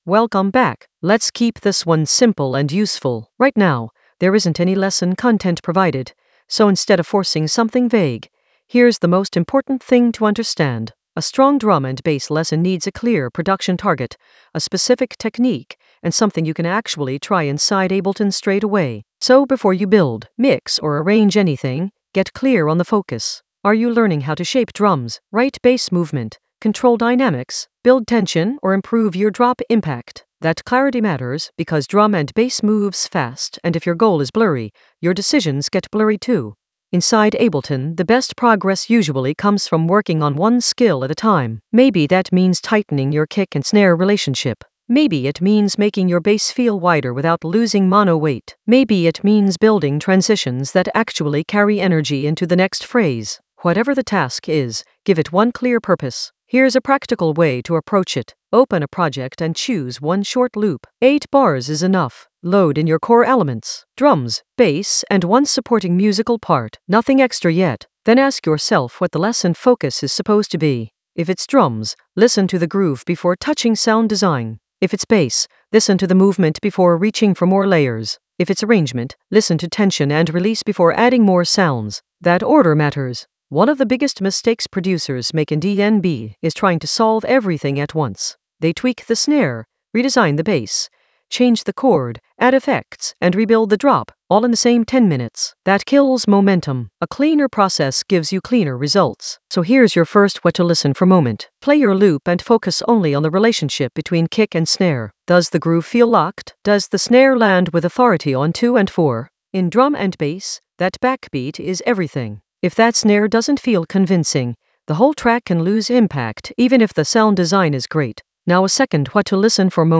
An AI-generated intermediate Ableton lesson focused on DJ Seduction touch: flip a piano-rush drop in Ableton Live 12 for melodic drum and bass lift in the Edits area of drum and bass production.
Narrated lesson audio
The voice track includes the tutorial plus extra teacher commentary.